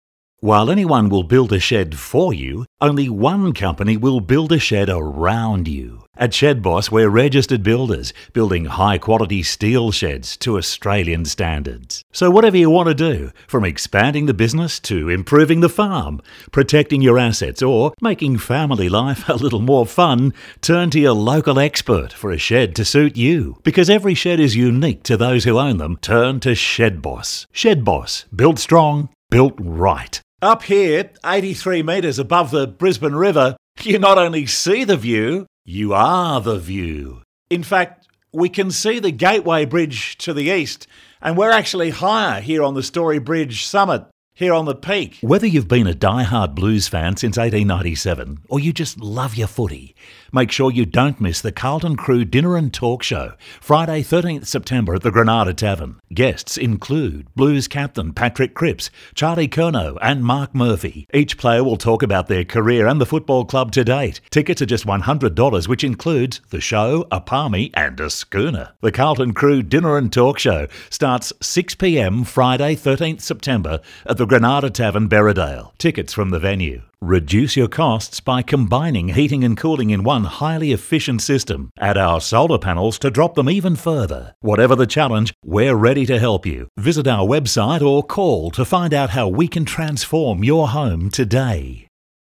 I'm a mid West guy who's been described as having a warm, caring guy next store voice and with a sense of humor.
Friendly and conversational....
English (North American) Adult (30-50) | Older Sound (50+)
0908demo_VR_Radio_ads.mp3